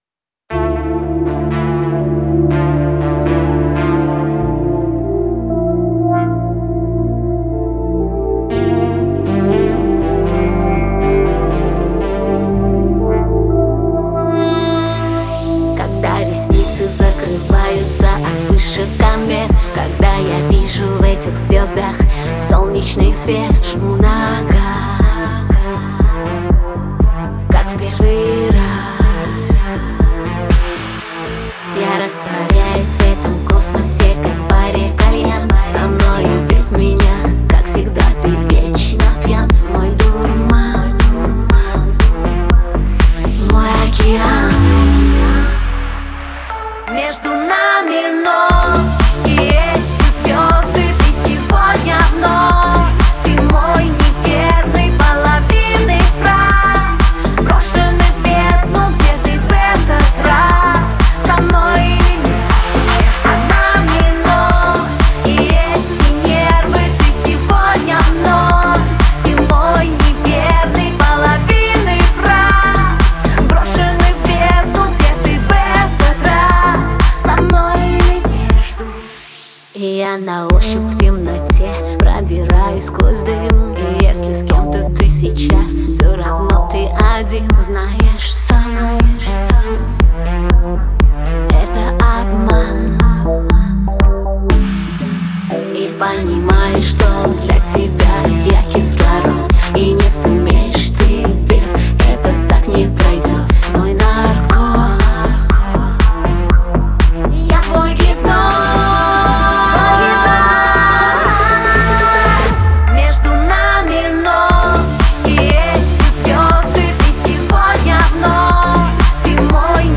мелодичная поп-песня